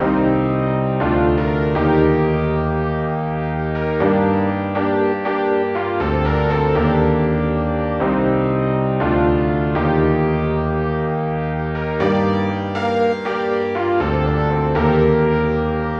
表情丰富的黄铜和弦 CDorian 120bpm 8bars
描述：用Harmor合成的号角，与钢琴键的尾音混合。 用C Dorian模式编写；它应该与G小调或A大调相协调。
标签： 120 bpm House Loops Synth Loops 2.69 MB wav Key : C
声道立体声